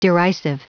Prononciation du mot derisive en anglais (fichier audio)
Prononciation du mot : derisive